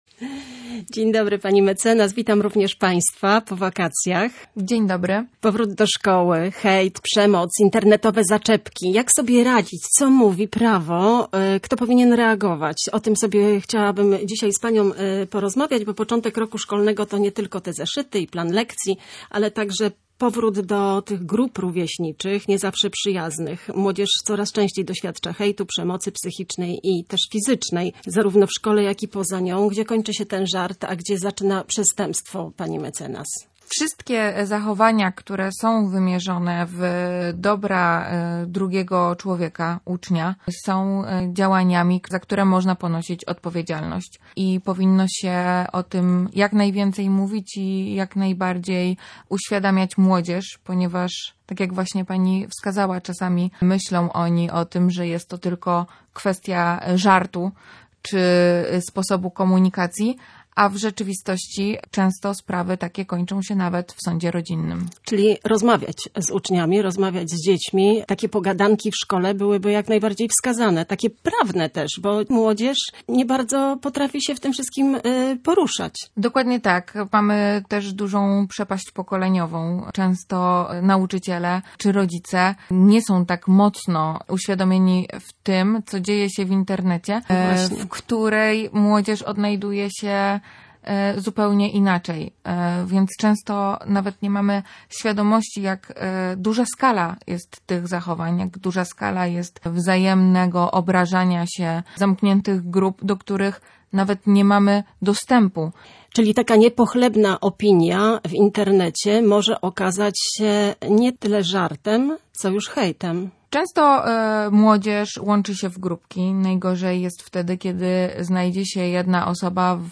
To rozmowa o prawie, ale przede wszystkim o bezpieczeństwie, empatii i odwadze w reagowaniu.